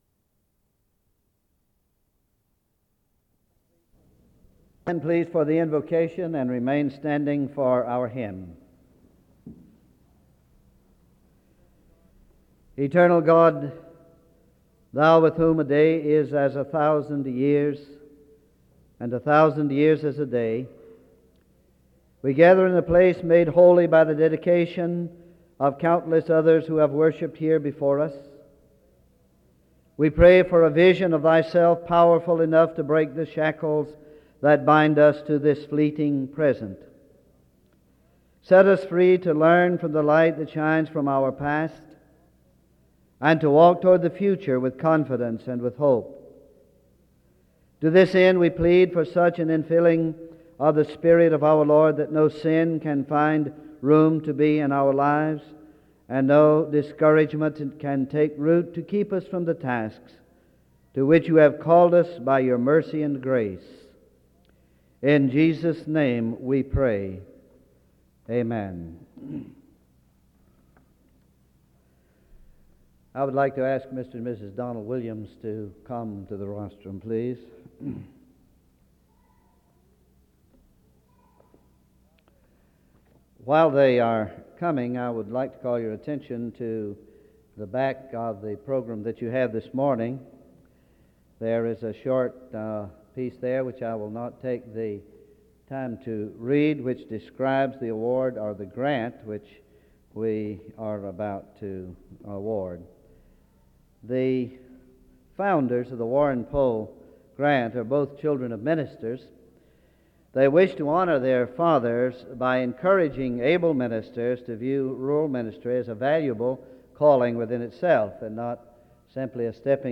The service begins with a prayer from 0:00-1:09. An award for a scholarship grant is given from 1:12-4:46. A scripture reading takes place from 4:48-7:16.
SEBTS Chapel and Special Event Recordings